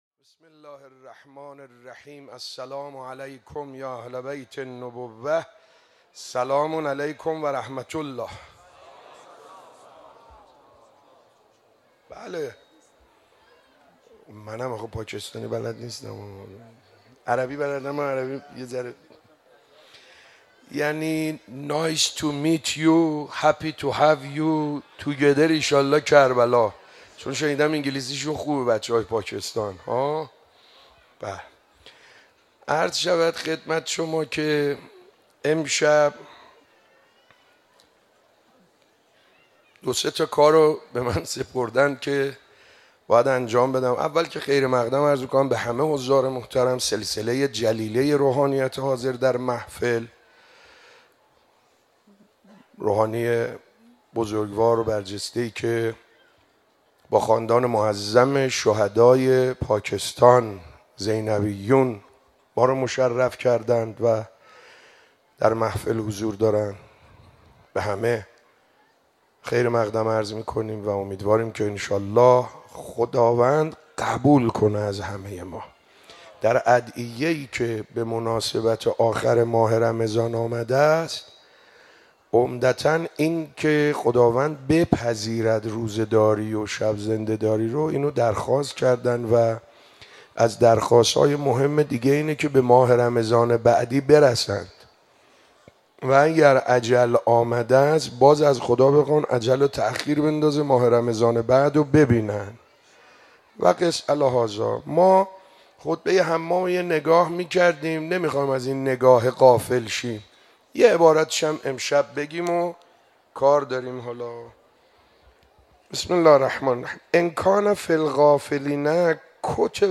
خیمه گاه - حاج سعید حدادیان - شب عید فطر95_صحبت